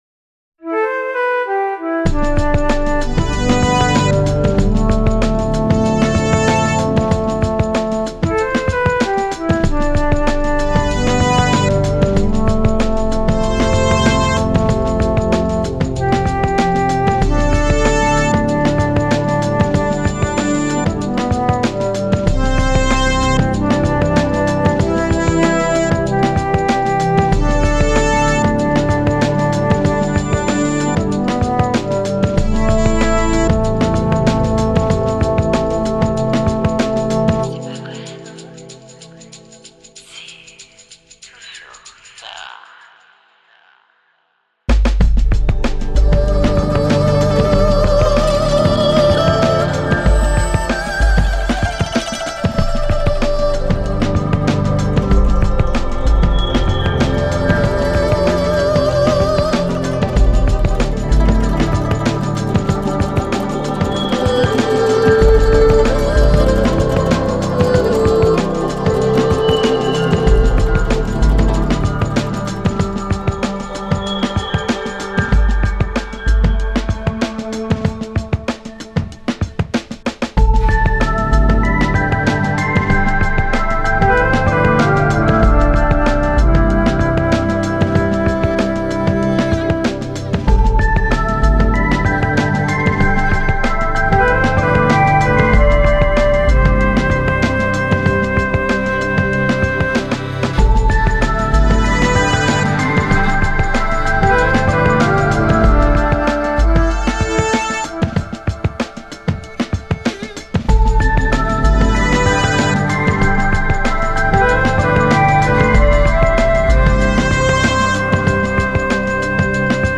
士郎の源流、ということで原曲をアンビエント調にアレンジしました。